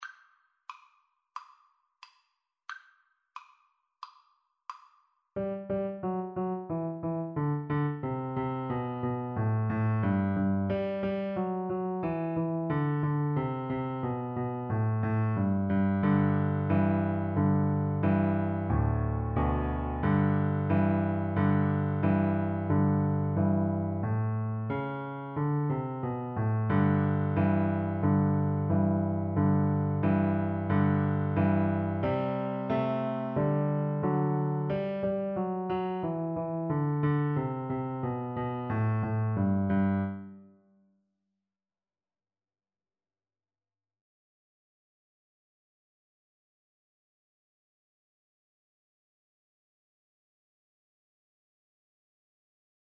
A beginners piece with a rock-like descending bass line.
March-like = 90
Pop (View more Pop Recorder Music)